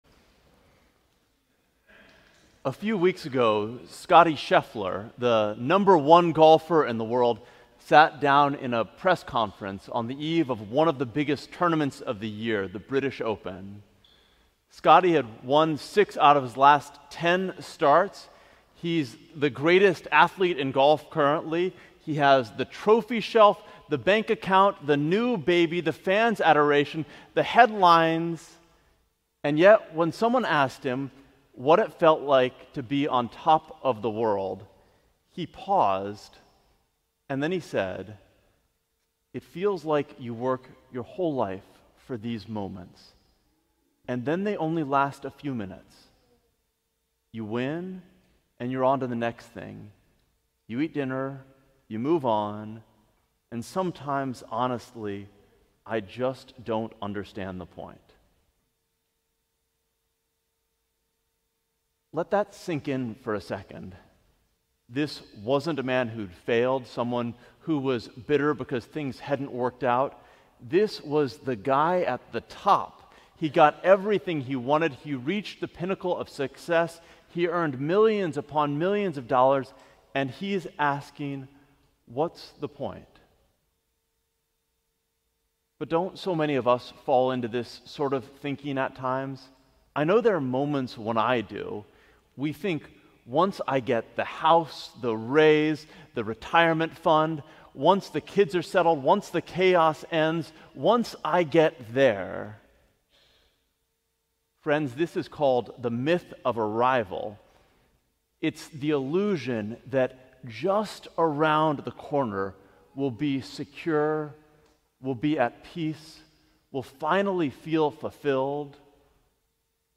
Sermon: Almost Living - St. John's Cathedral